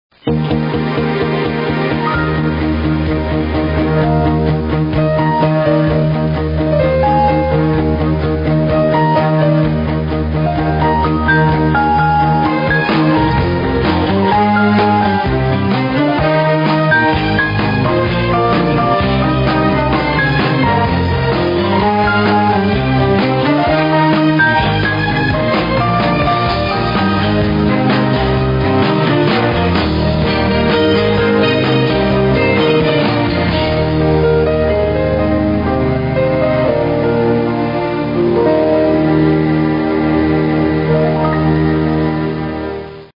The Music...The Themes